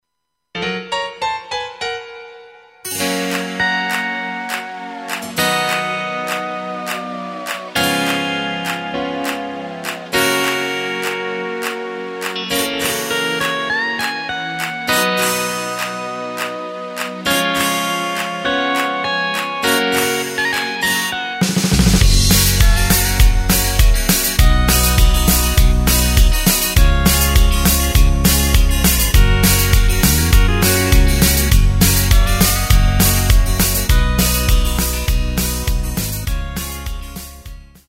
Takt:          4/4
Tempo:         101.00
Tonart:            Gb
Country-Song aus dem Jahr 2021!
Playback mp3 Demo